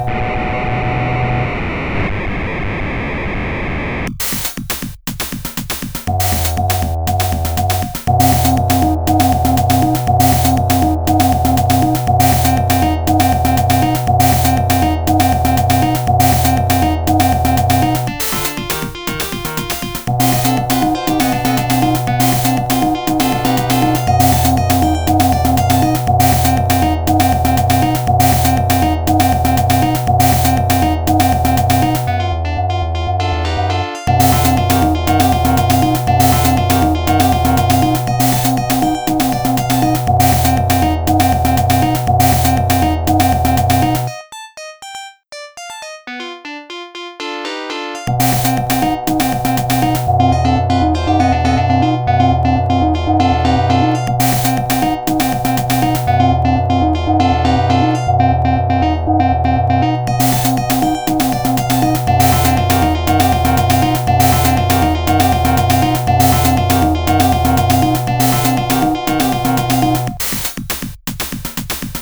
Dynamically hypnotizing retro melody